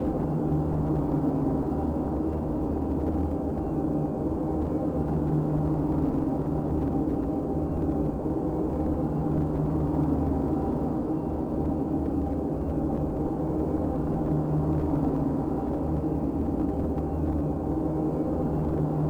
volcano.wav